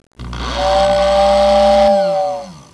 The whistle sounds like a actual train whistle. It is not a shrill sounding whistle, but it sounds realistic.
In addition to the whistle, you can here the motor running that turns the impeller.
Train_WhistleR.wav